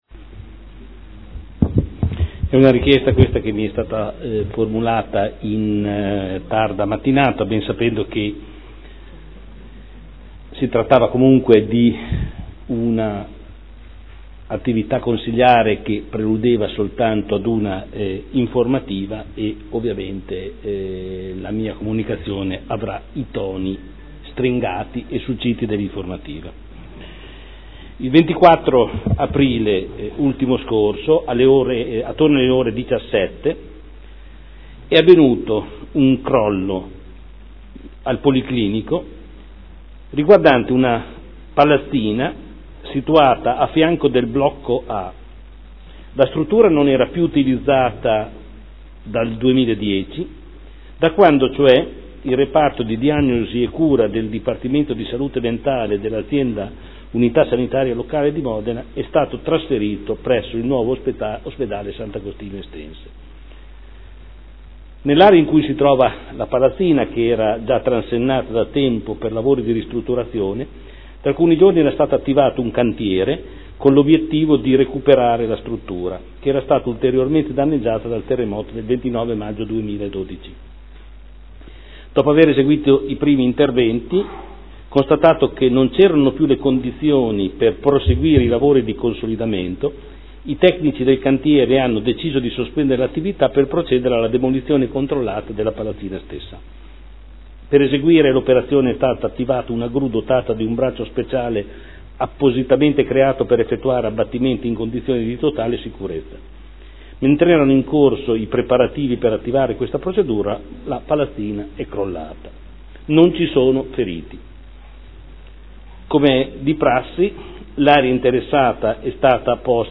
Seduta del 29/04/2012. Informativa sul crollo della palazzina al Policlinico.